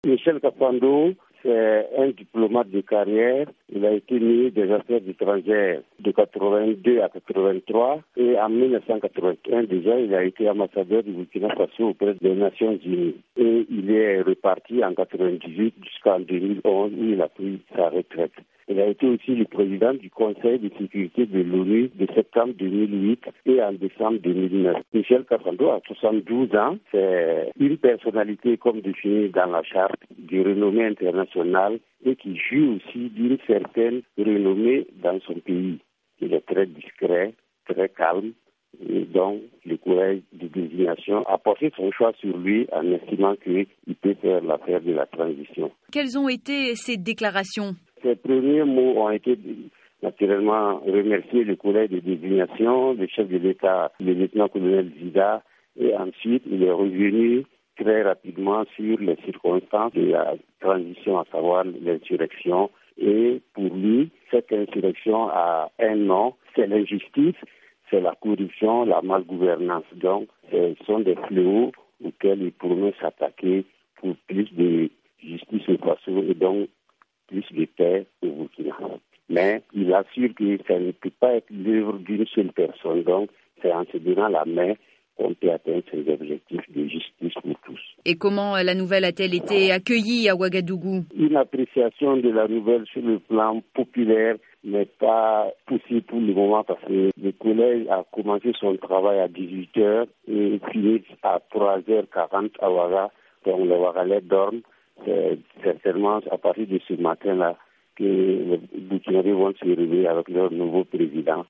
Retour sur le choix de Michel Kafando avec notre correspondant à Ouagadougou :